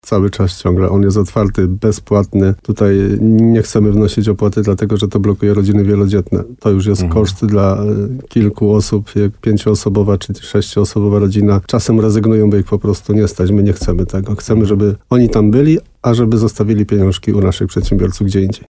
– mówił w programie Słowo za Słowo w radiu RDN Nowy Sącz wójt gminy Rytro Jan Kotarba i podkreślał, że przyjezdni cały czas mogą wchodzić na zamek, póki wykonawca nie zacznie przygotowywać się tam do rozpoczęcia prac.